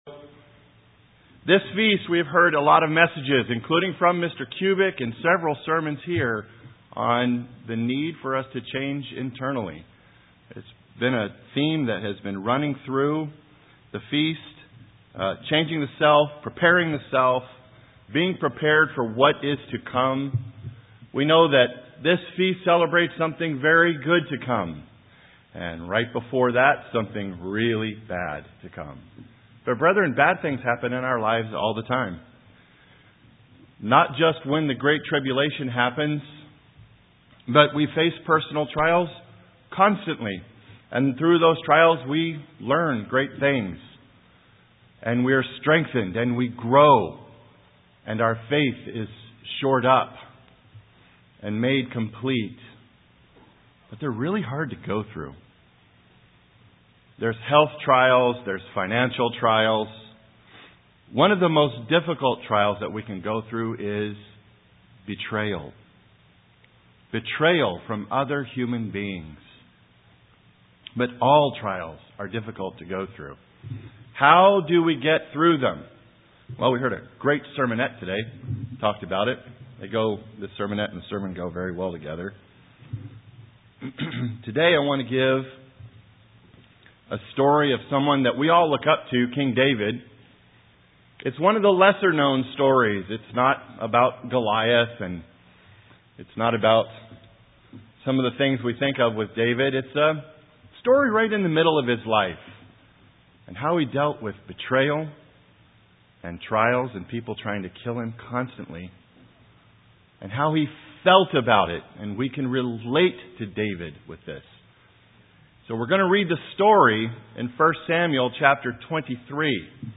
Day seven FOT New Braunfels.
UCG Sermon Transcript This transcript was generated by AI and may contain errors.